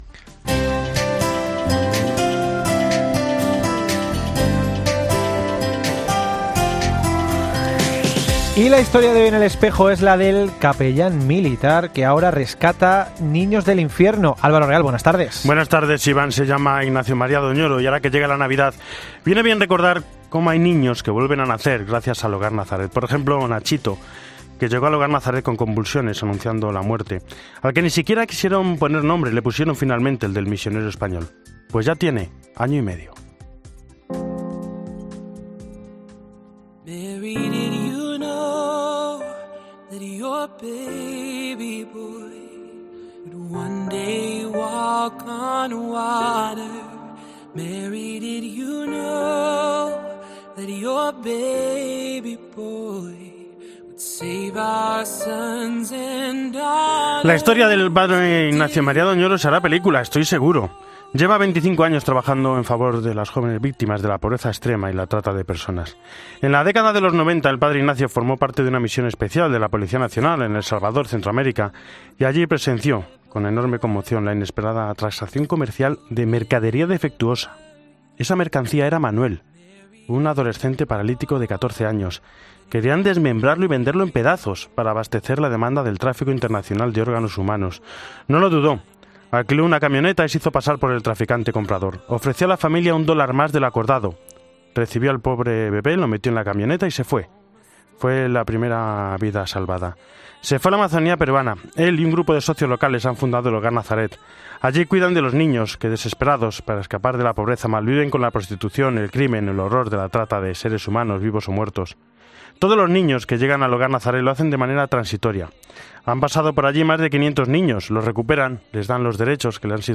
Iglesia en Roma y el mundo.